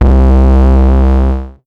Index of /90_sSampleCDs/Zero-G - Total Drum Bass/Instruments - 1/track26 (Basses)
06 Fog C.wav